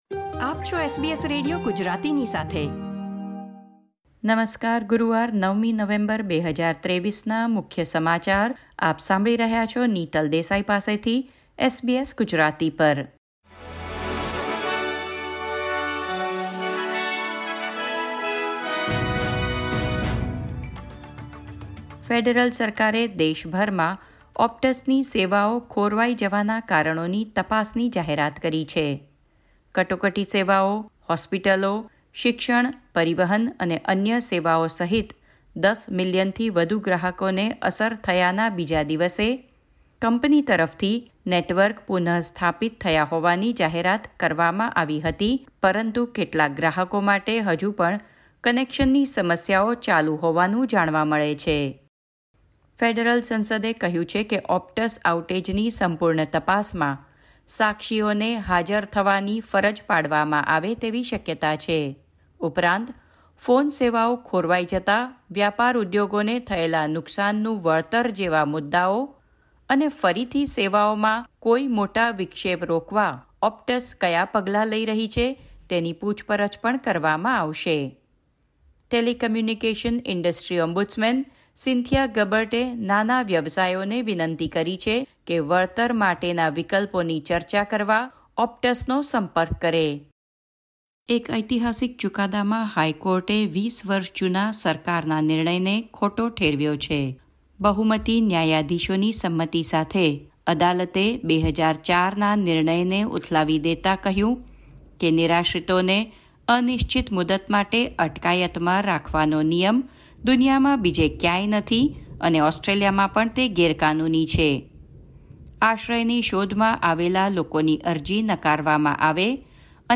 SBS Gujarati News Bulletin 9 November 2023